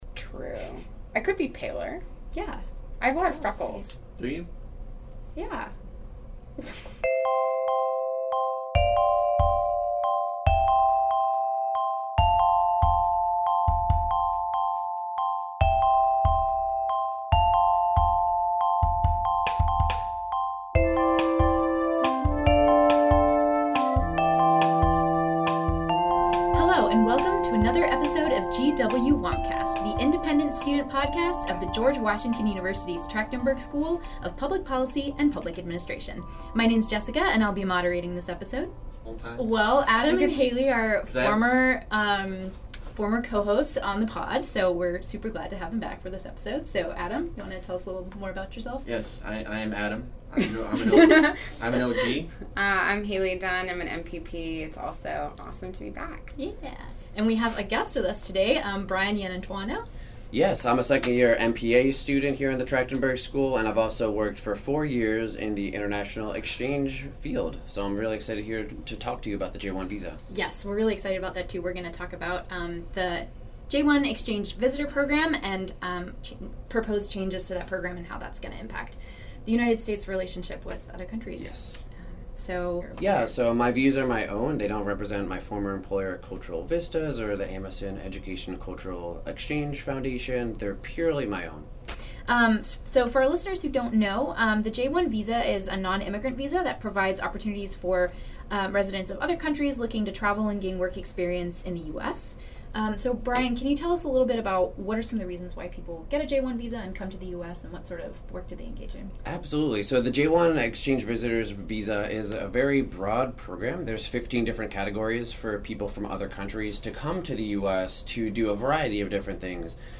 j-1-visa-interview-2_1_18.mp3